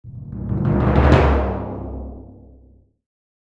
Cartoon-timpani-drum-roll-accelerating.mp3